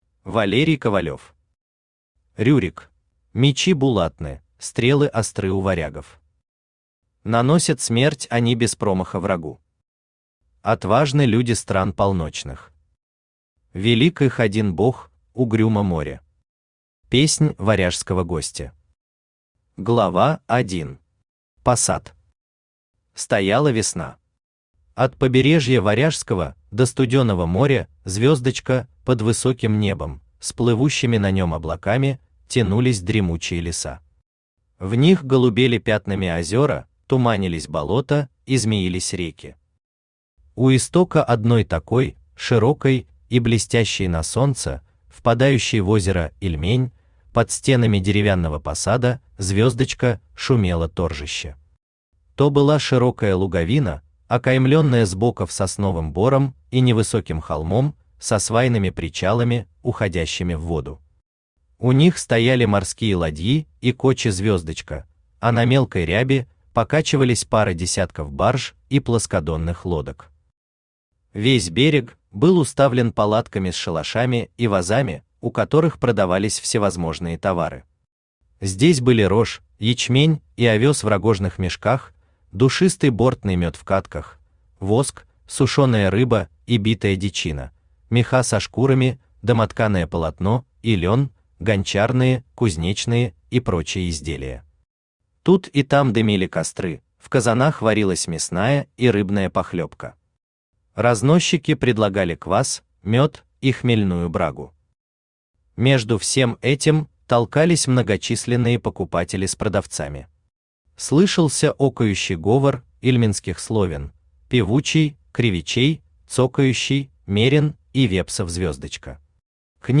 Aудиокнига Рюрик Автор Валерий Николаевич Ковалев Читает аудиокнигу Авточтец ЛитРес.